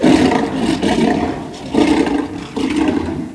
Index of /server/sound/weapons/tfa_cso/m95tiger
tiger.wav